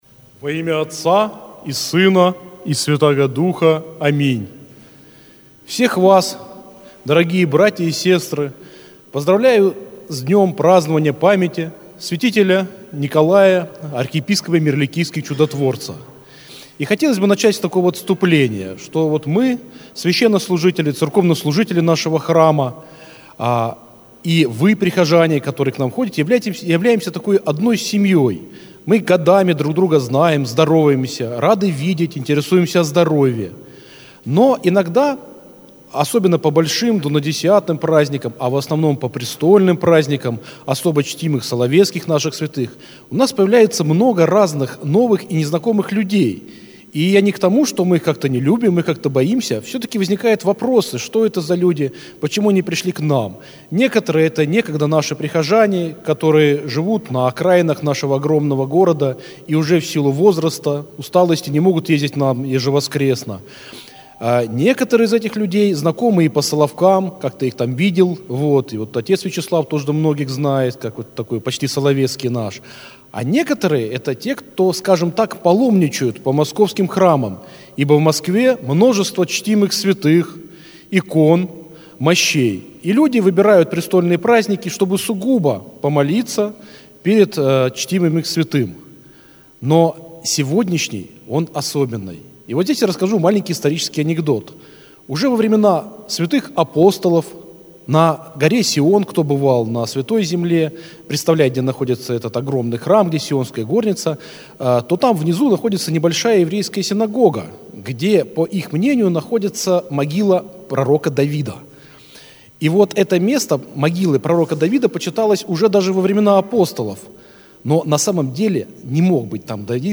Аудиозапись проповеди
Храм Великомученика и Победоносца Георгия в Ендове